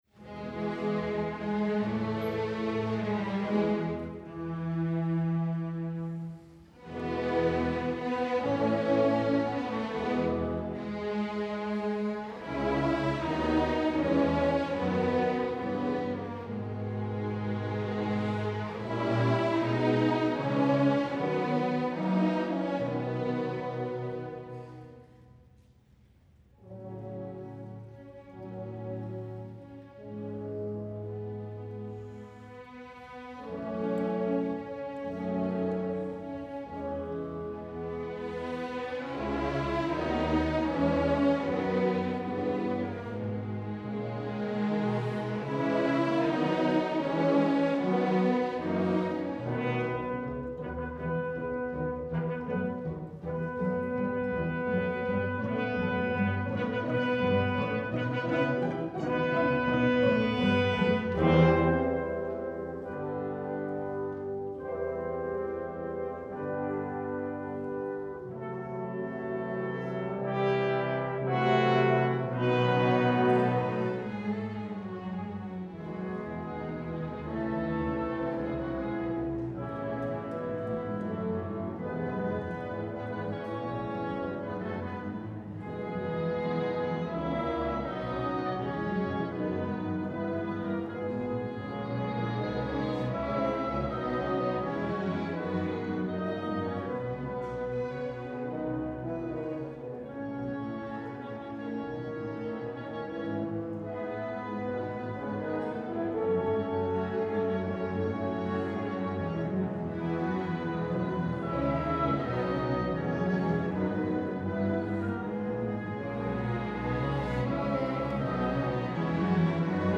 Audio recording of East Carolina University's Symphony Orchestra, April 20, 2013 - ECU Digital Collections